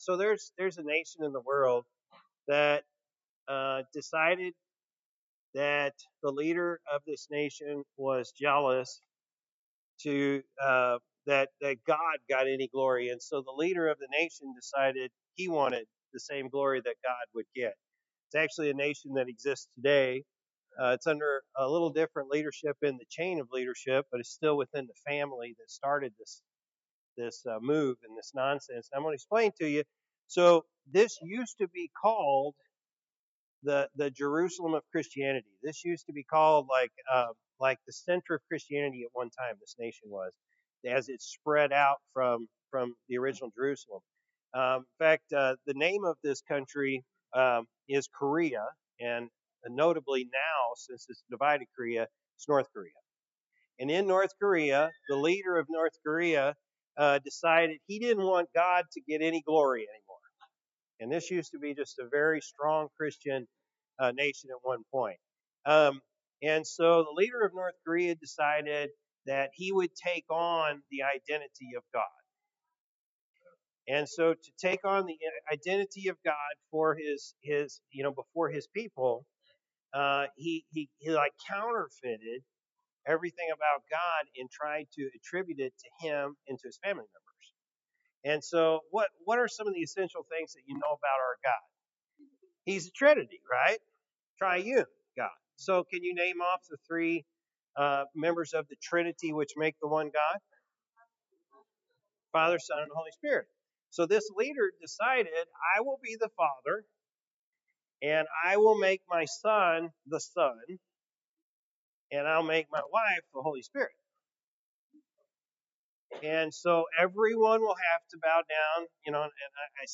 February-2-2025-Morning-Service.mp3